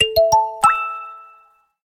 notification_alarm.mp3